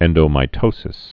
(ĕndō-mī-tōsĭs)